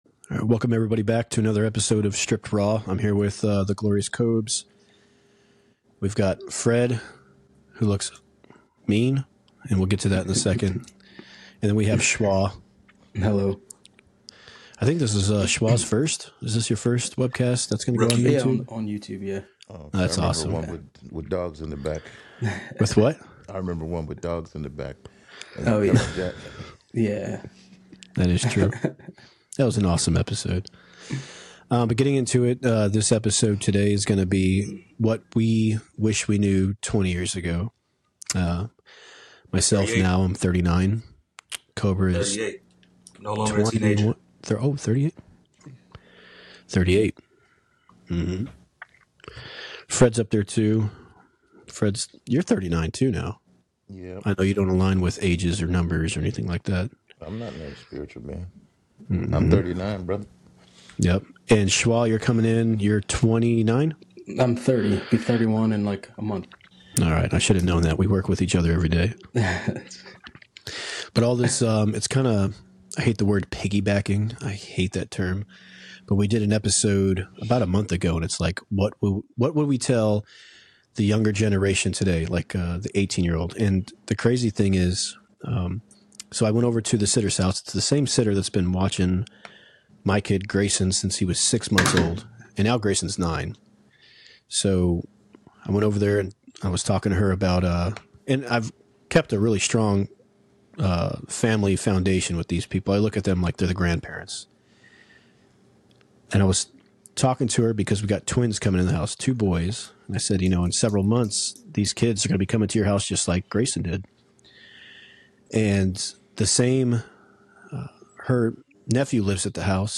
In this conversation, we reflect on the lessons we wish we had learned earlier in life, focusing on financial wisdom, the importance of self-perception, navigating relationships, emotional growth, and the value of community.